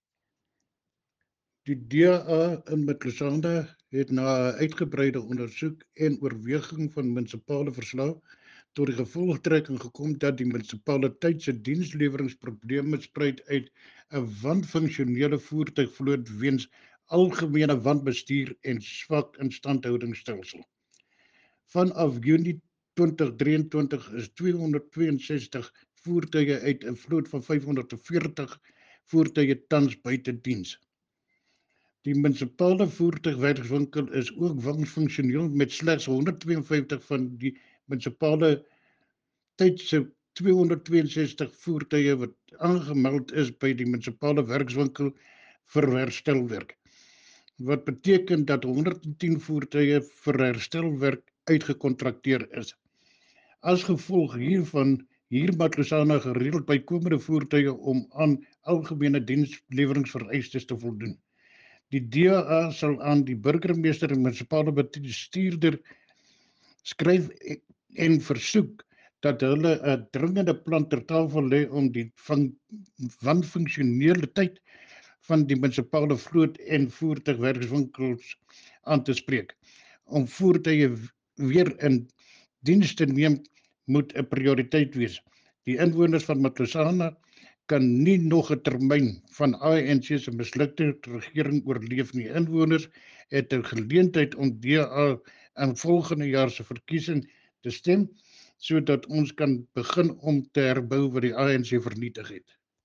Note to Broadcasters: Please find linked soundbites in
Cllr-Johan-Bornman-Municipal-Fleet-Eng.mp3